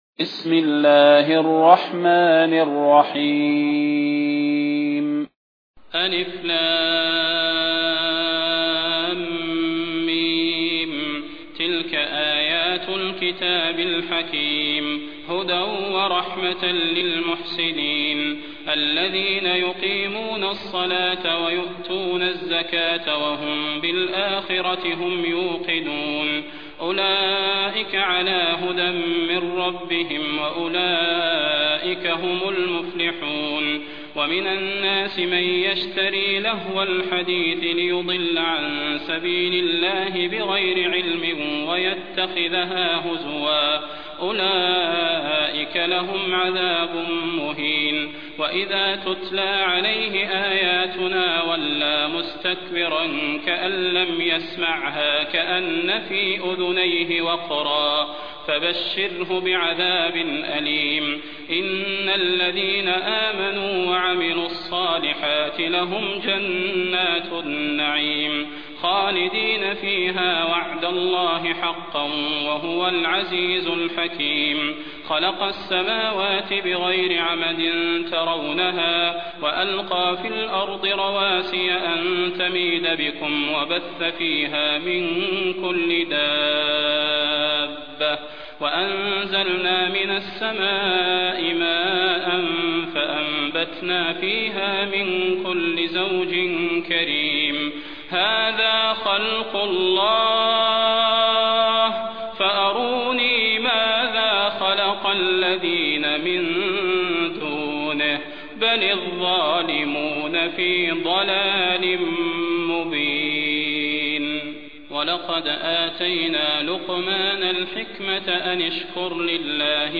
المكان: المسجد النبوي الشيخ: فضيلة الشيخ د. صلاح بن محمد البدير فضيلة الشيخ د. صلاح بن محمد البدير لقمان